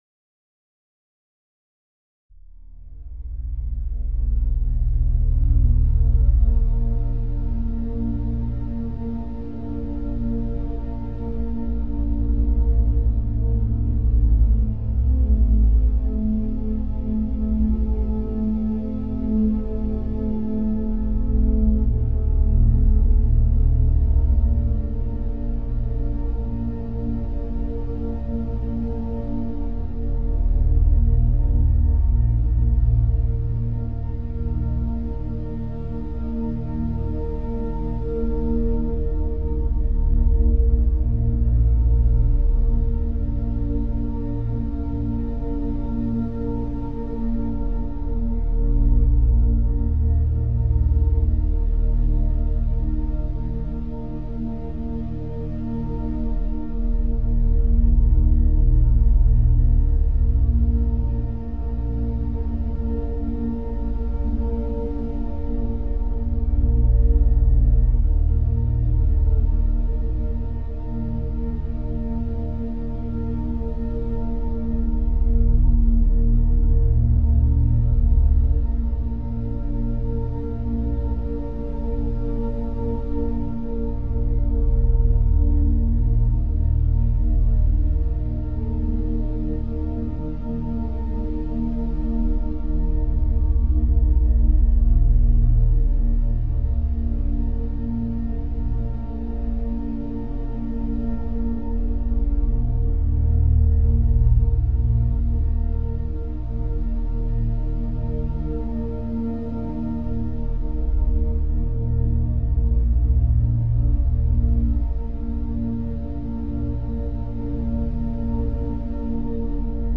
Full Moon - 432 hz Binaural Beats for Grounding, Peace, and Harmony
Calm Chic sound rituals - a softer way to focus.